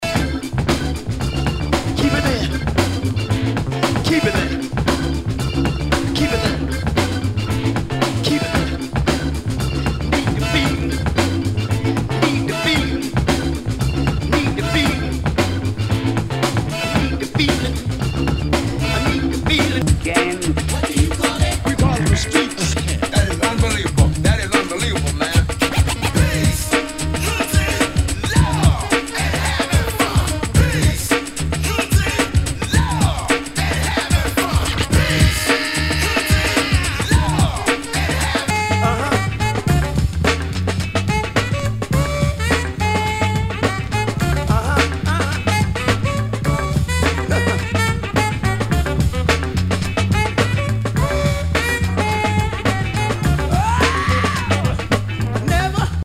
SOUL/FUNK/DISCO
全体にチリノイズが入ります。